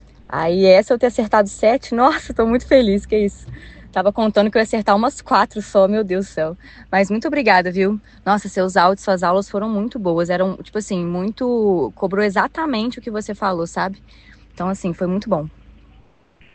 Alguns Áudios dos Alunos
AUDIO-DEPOIMENTO-3.m4a